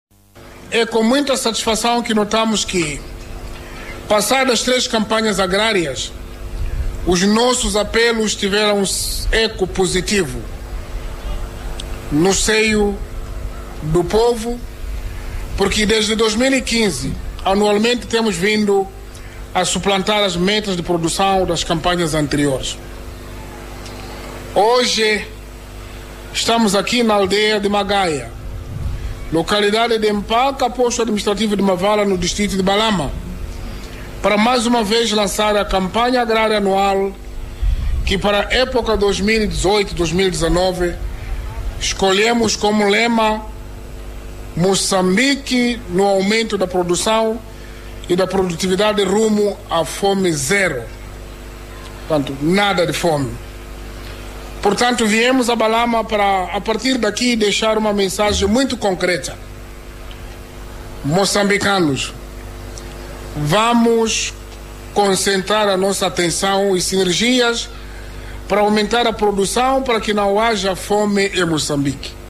O Presidente da República, Filipe Jacinto Nyusi, procedeu hoje, dia 25 de Outubro de 2018, na localidade de Mpaca, distrito de Balama, em Cabo Delgado, ao Lançamento da Campanha Agrária 2018/2019, que decorrerá sob o lema “Moçambique no aumento da produção e da produtividade rumo a fome zero”.